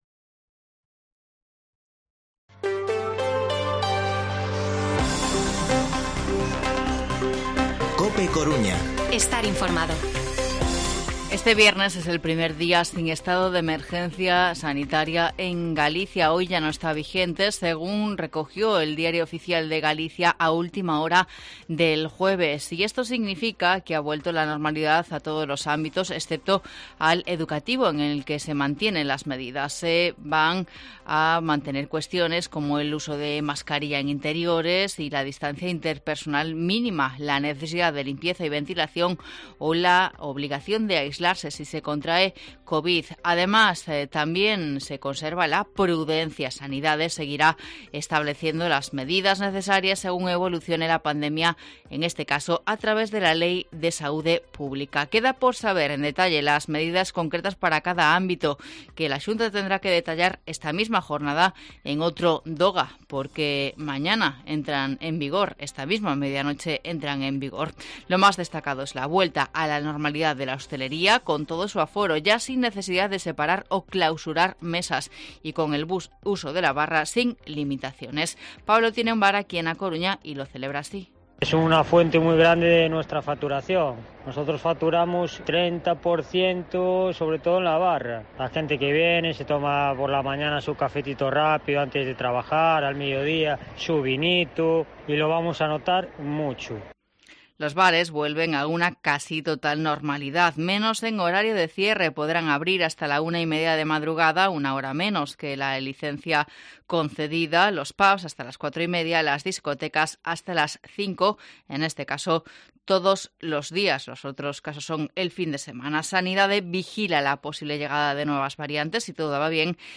Informativo Mediodía COPE Coruña viernes, 22 de octubre de 2021 14:20-14:30